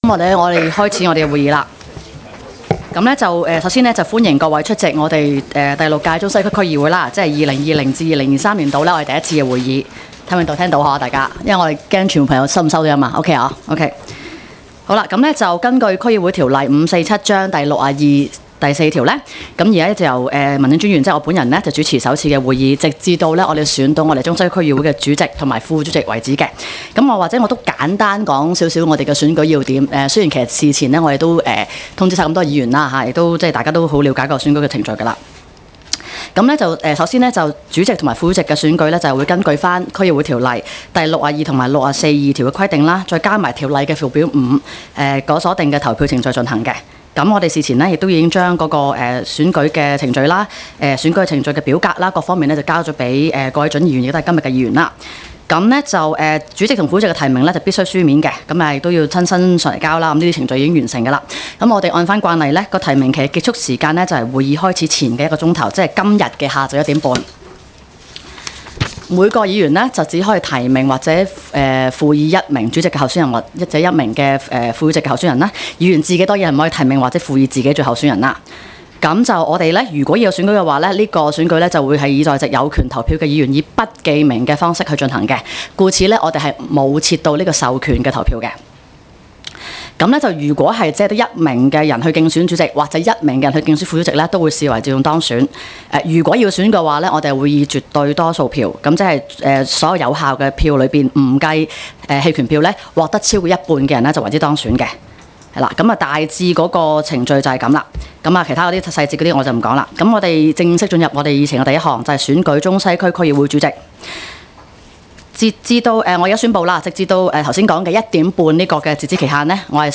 区议会大会的录音记录
中西区区议会会议室